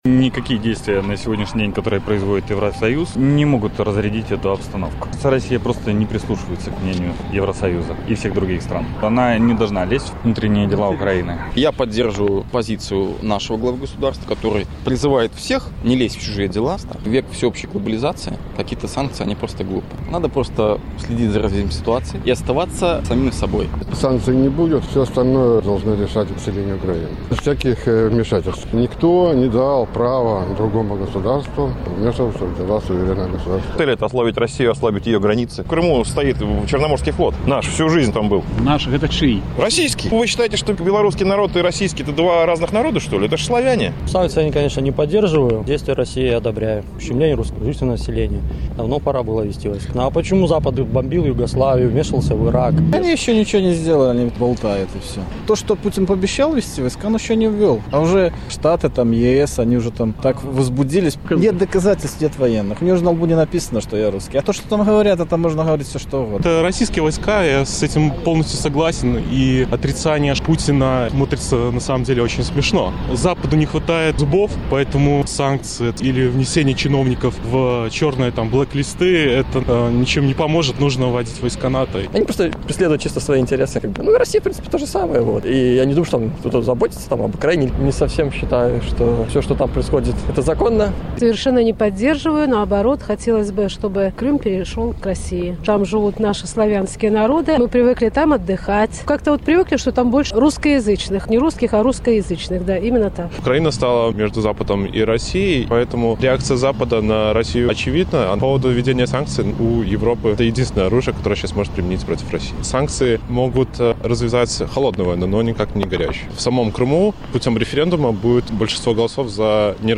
Адказвалі жыхары Магілёва